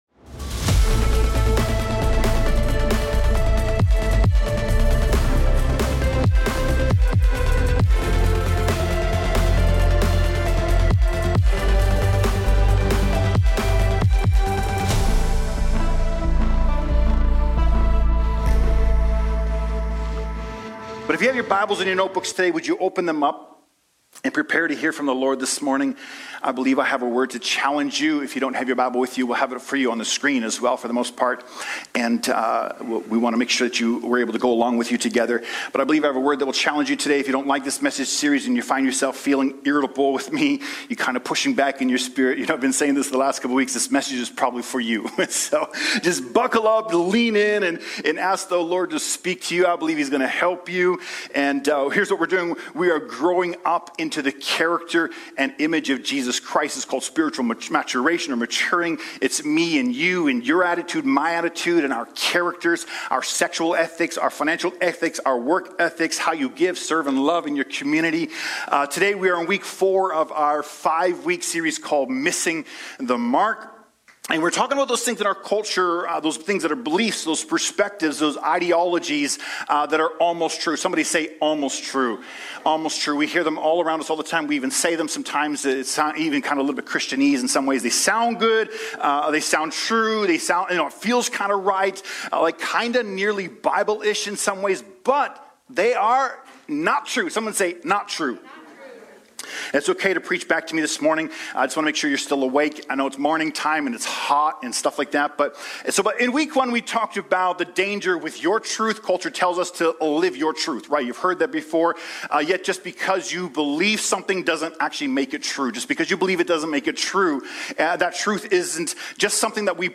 Download Download Missing the Mark Current Sermon Is Happiness the End Goal?